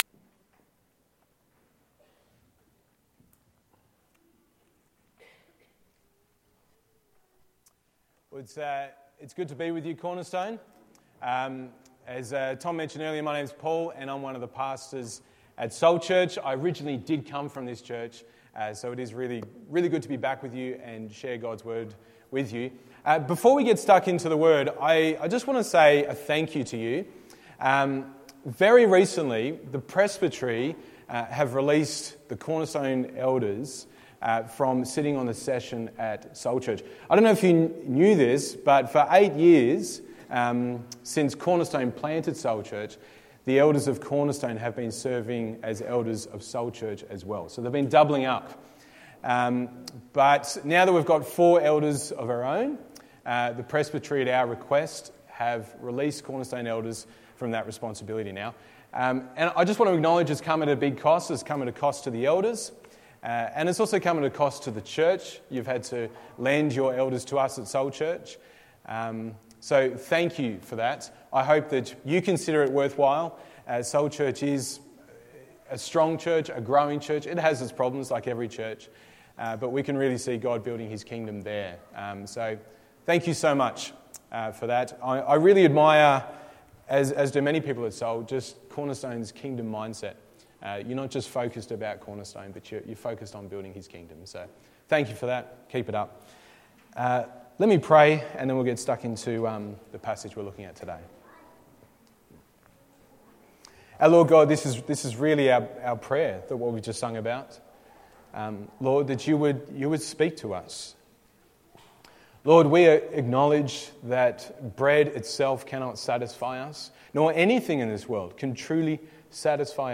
Text: Mark 8:22-26 Sermon